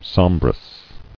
[som·brous]